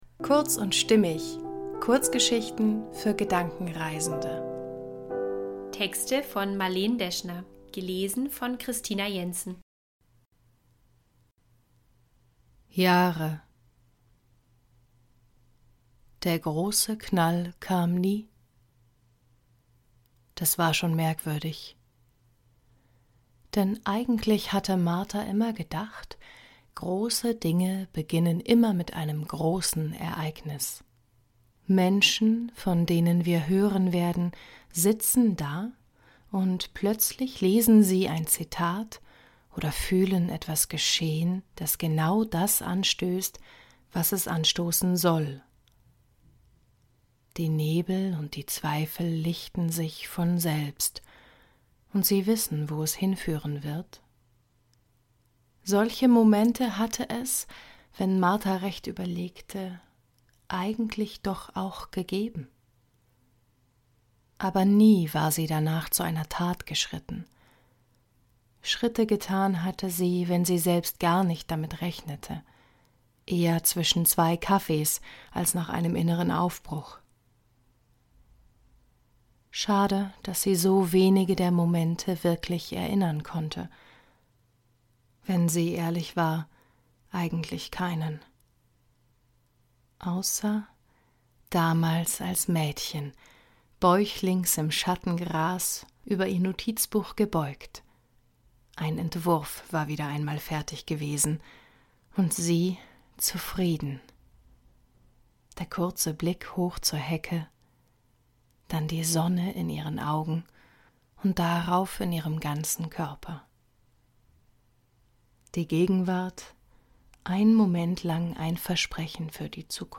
kurz & stimmig - Kurzgeschichten für Gedankenreisende